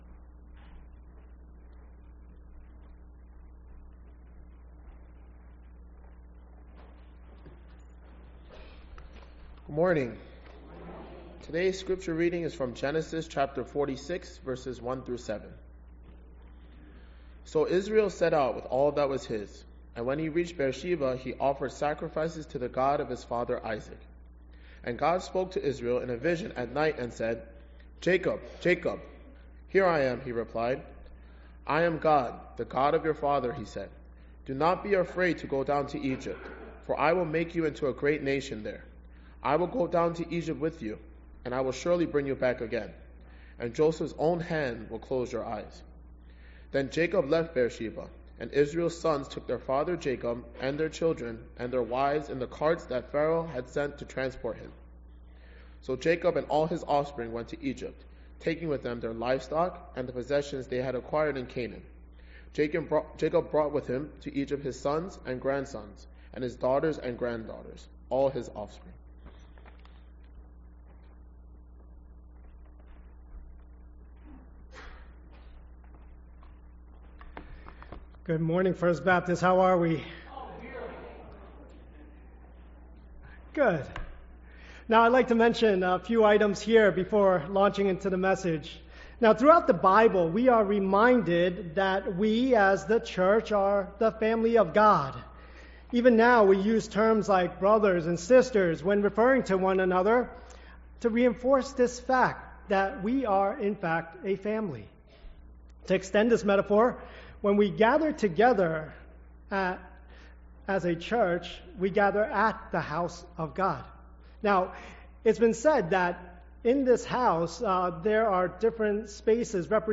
English Sermons | First Baptist Church of Flushing